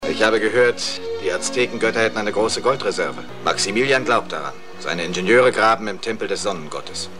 Lex Barker: Dr. Karl Sternau,  Dubbing actor: Gert Günther Hoffmann
Sound file of German dubbing actor (113 Kb)